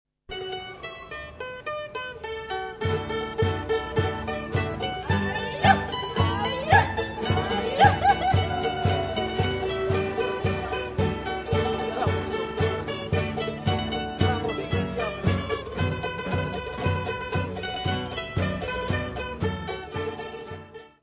: stereo; 12 cm
Área:  Tradições Nacionais